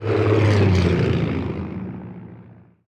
planesSpeedUp.wav